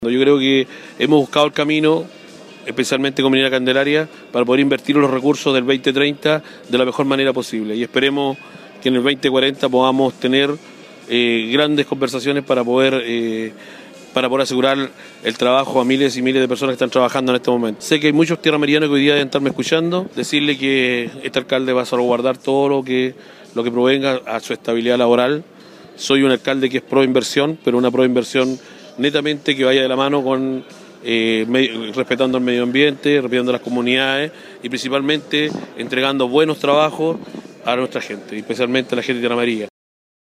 Con un emotivo acto en las instalaciones de Minera Candelaria en Tierra Amarilla, la empresa celebró el Día de la Minería con sus trabajadores